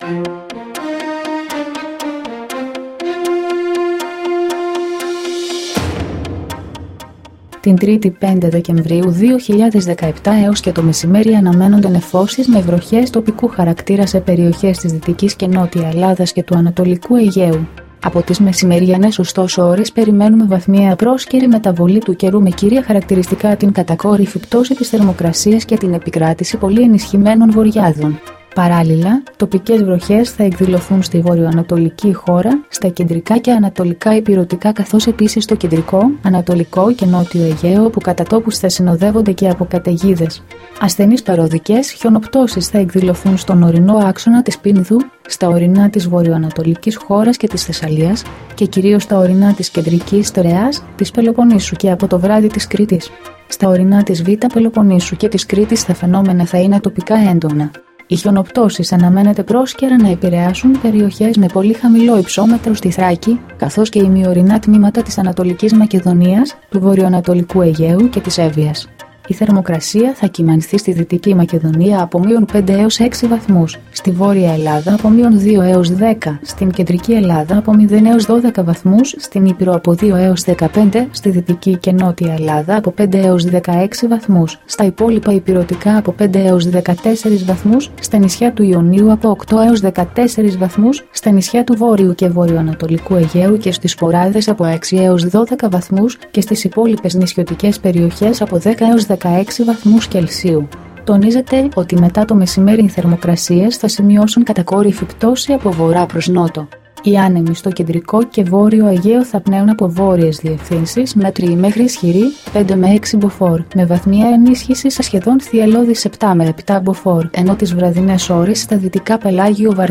dailyforecast-29.mp3